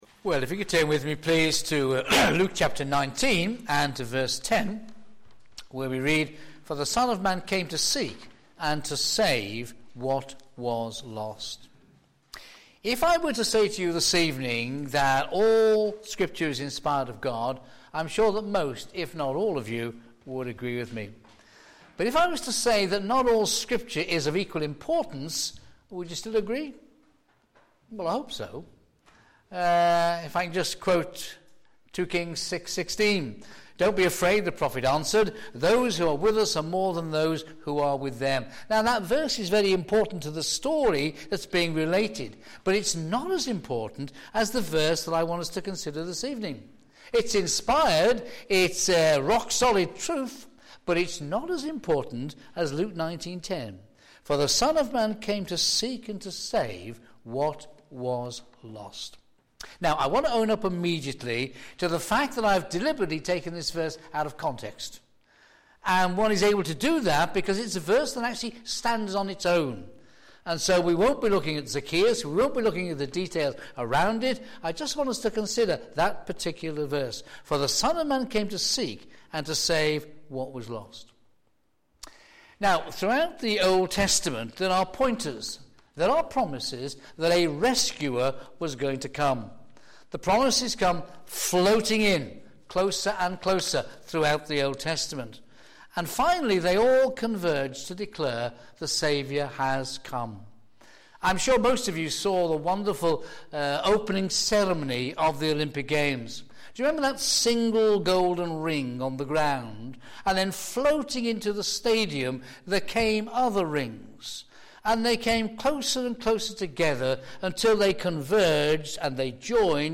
Theme: Seek and save the lost Sermon In the search box below